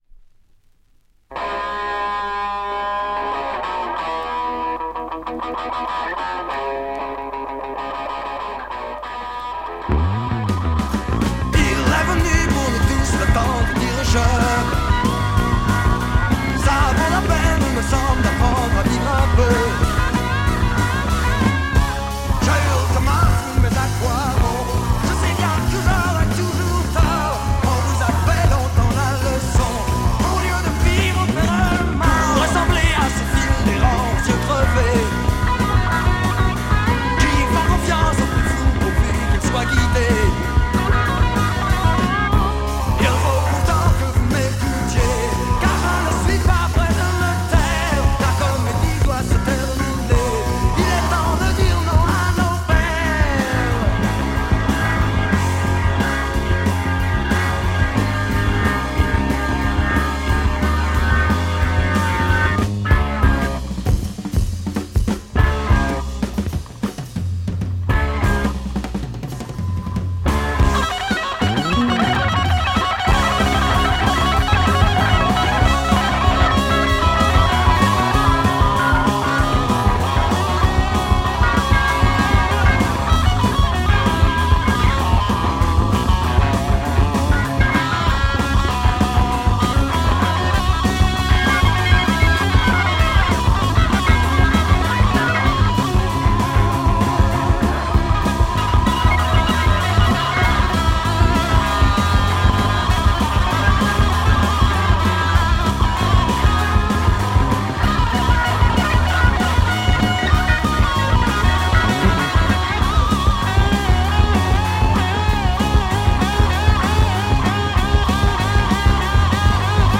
Secret ref Killer French Hard rock psych Glam 2 siders!